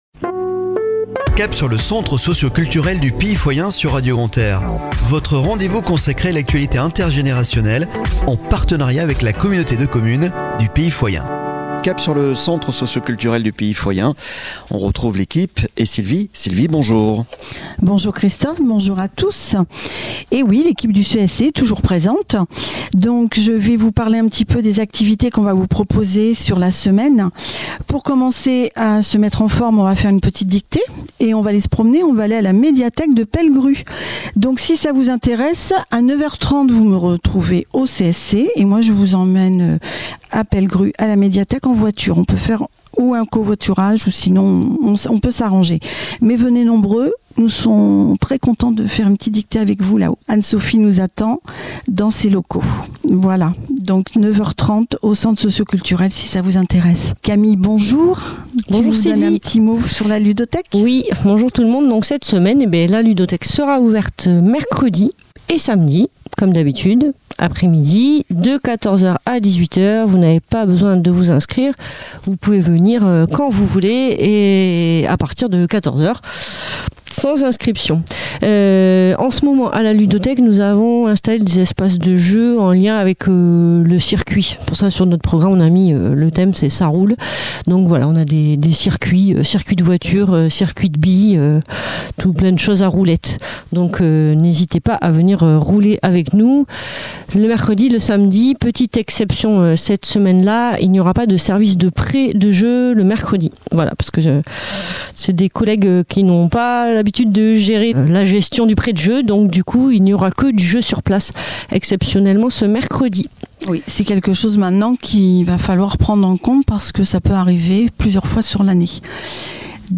Chronique de la semaine du 06 au 12 Février 2023 !